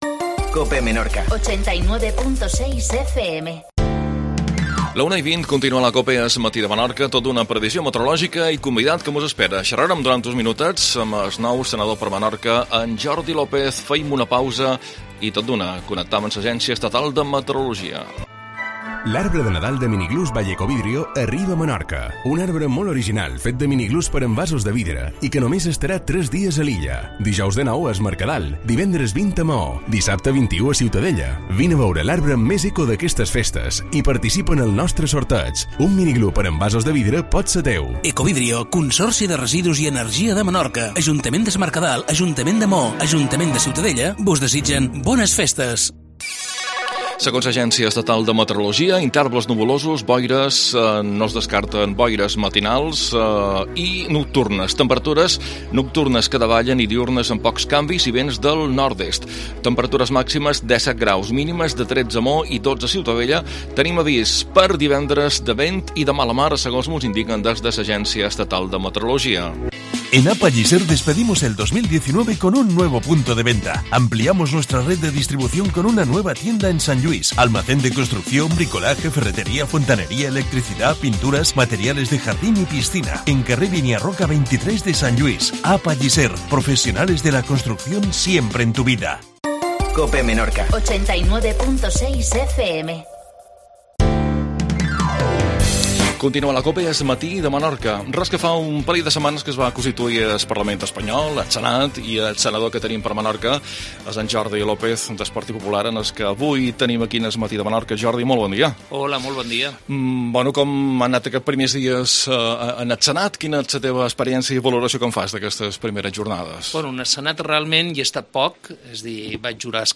previsio meteorologica. Jordi Lopez, senador per Menorca.